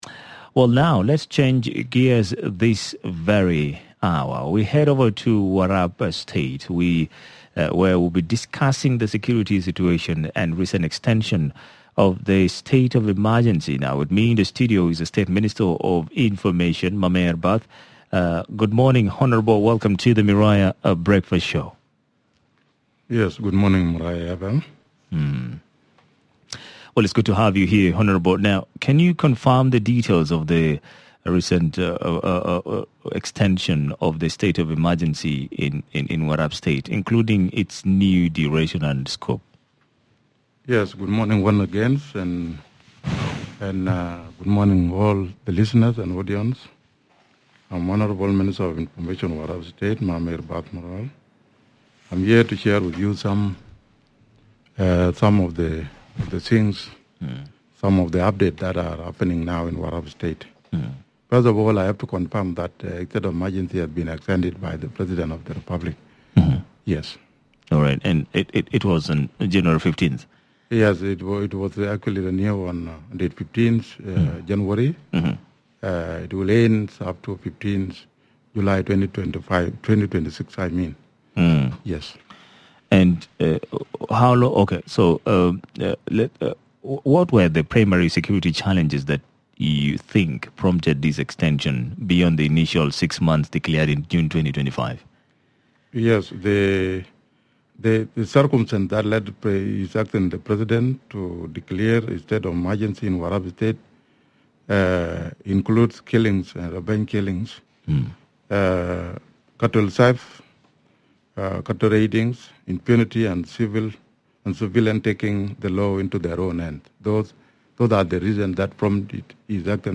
On the Miraya Breakfast Show, the Warrap State Minister of Information Mamer Bath discusses the extension of the state of emergency to 15 July 2026 due to ongoing intercommunal violence, civilian killings, and widespread illegal firearms.